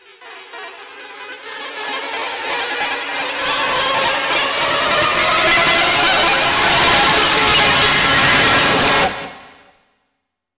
Index of /90_sSampleCDs/Spectrasonics - Bizarre Guitar/Partition H/07 SCRAPE SW